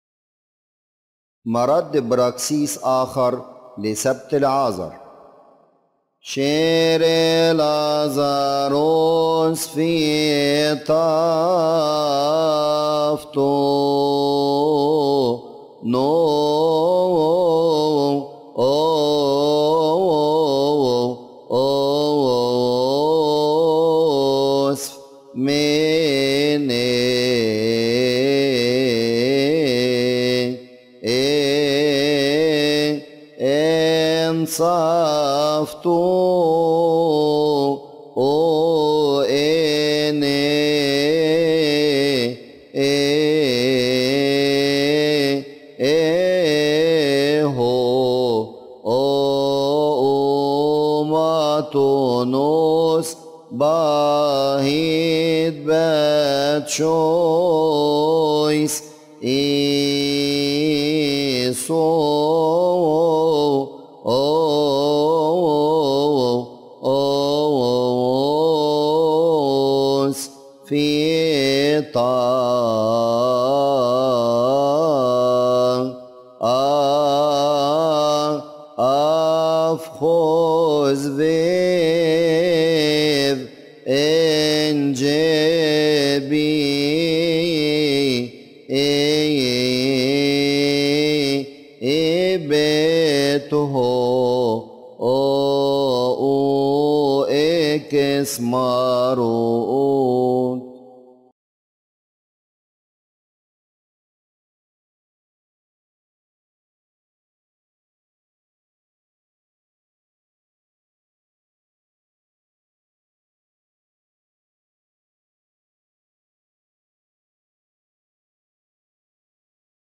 مكتبة الألحان
Praxis-response-Lazarus-saturday.mp3